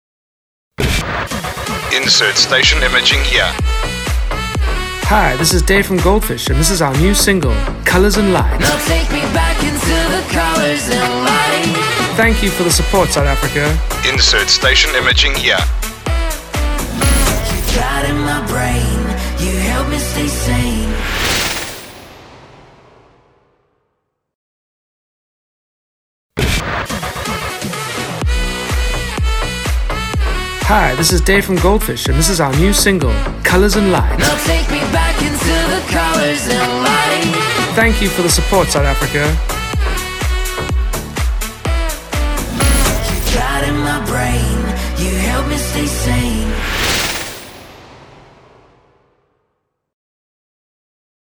Imaging
Sweeper